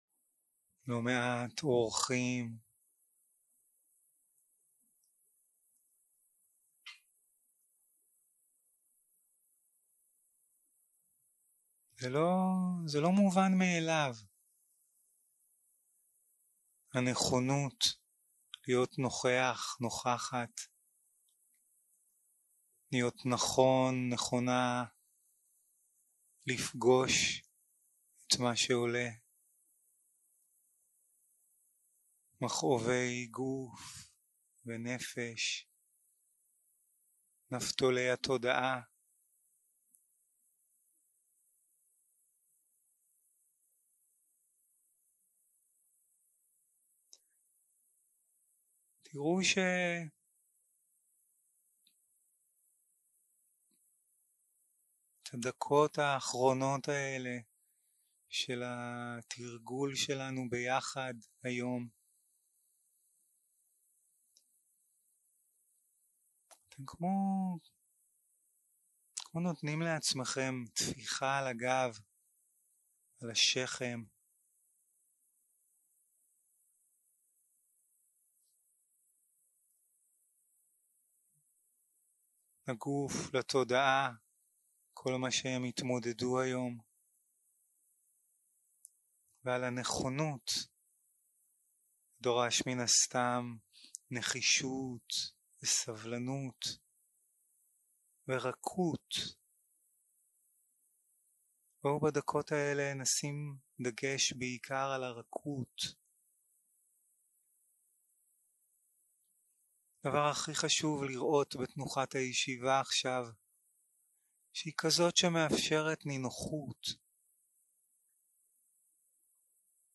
יום 4 - הקלטה 11 - לילה - מדיטציה מונחית